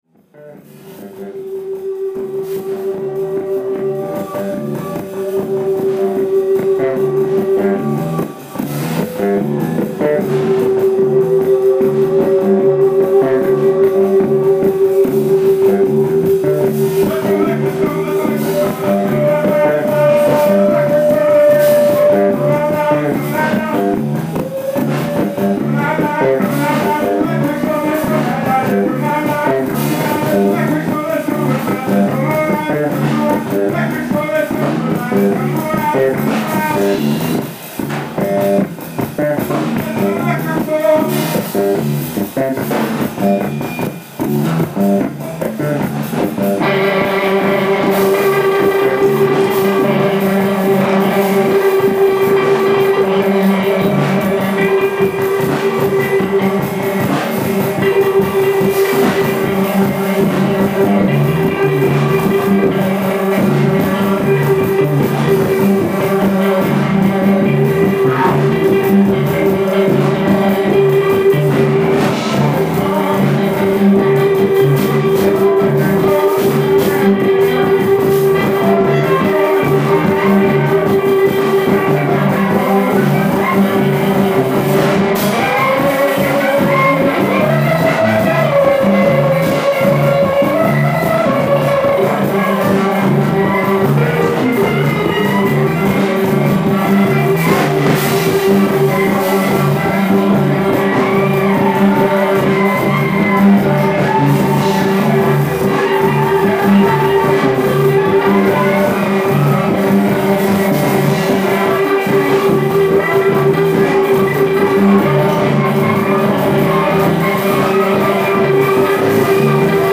ALL MUSIC IS IMPROVISED ON SITE
guitar/voice
bass
sax
drums